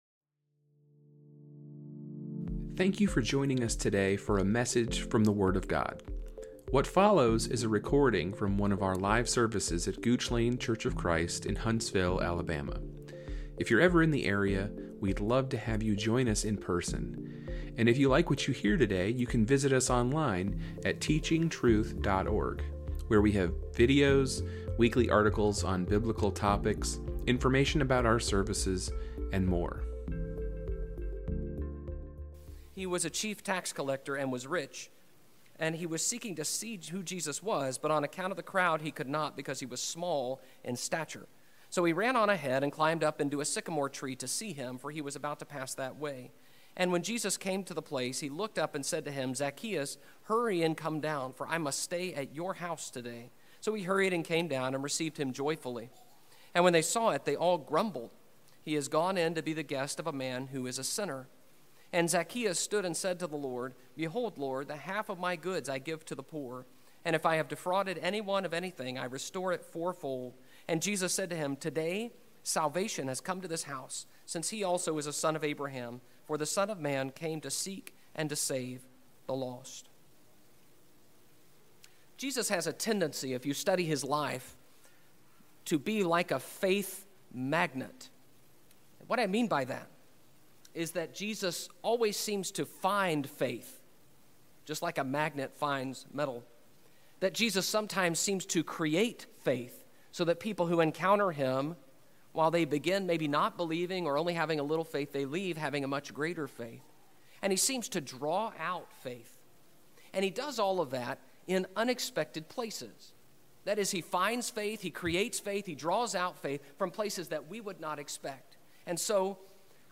A recording of the Sunday evening sermon from our gospel meeting